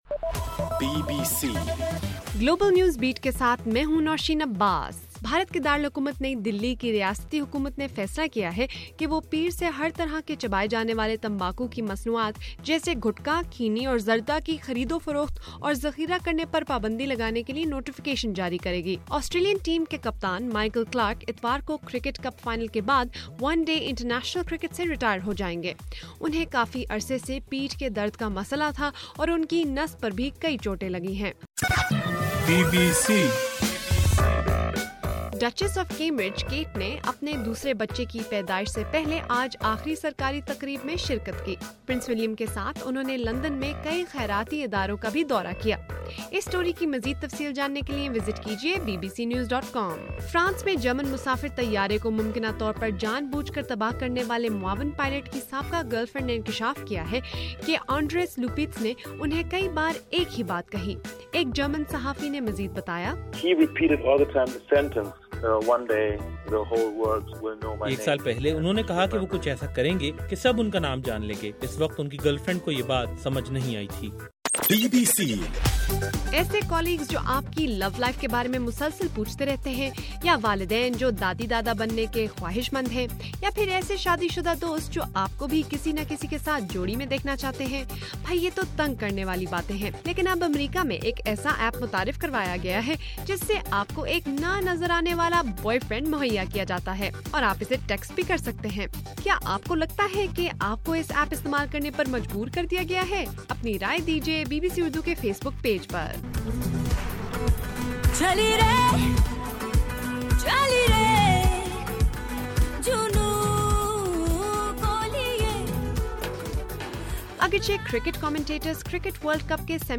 مارچ 28: رات 10 بجے کا گلوبل نیوز بیٹ بُلیٹن